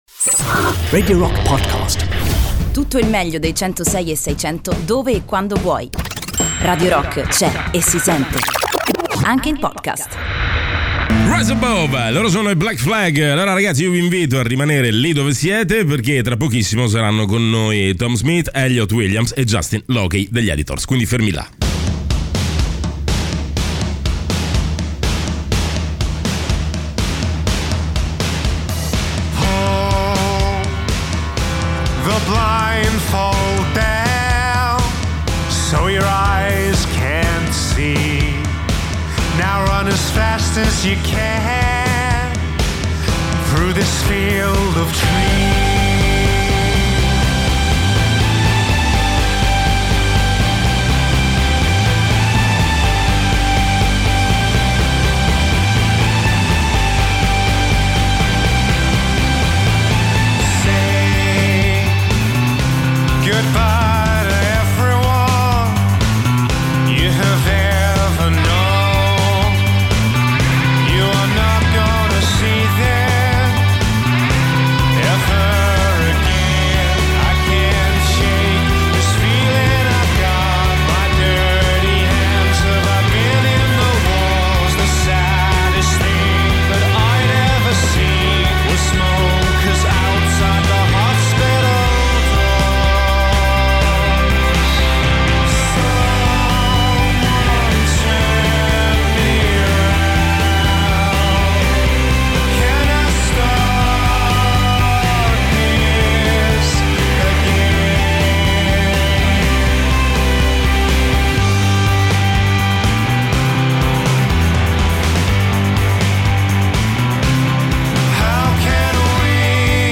Intervista: Editors (06-12-19)
Gli Editors (Tom Smith, Russell Leetch e Justin Lockey) ospiti negli studi di Radio Rock